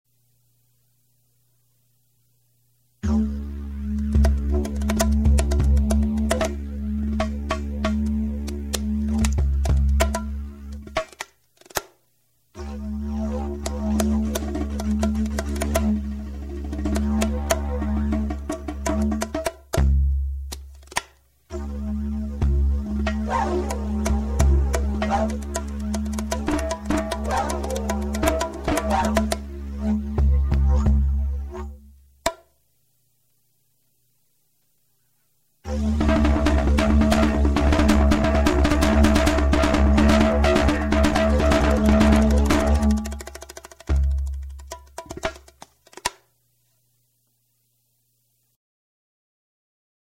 Bongos, Congas, Djiembe, and Dijerydew